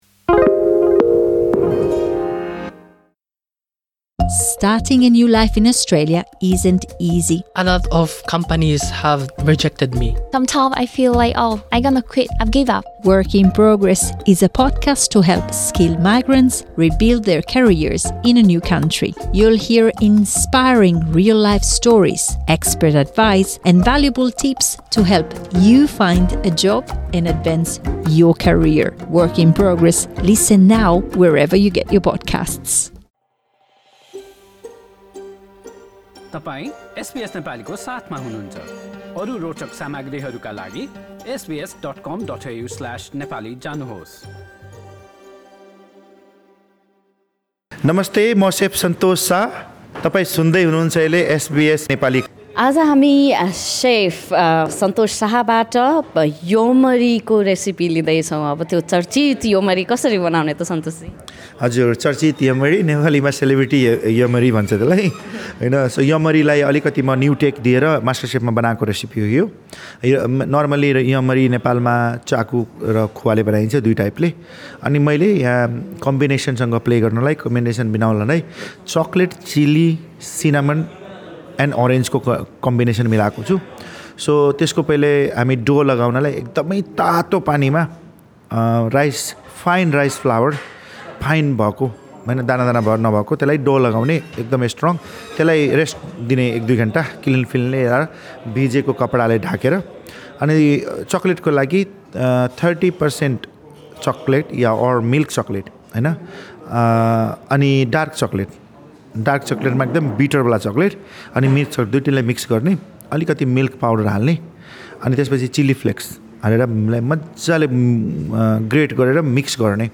योमरी कसरी बनाउने भन्नेबारे चाहिँ सेफ सन्तोष साहसँग गरिएको कुराकानी सुन्नुहोस्।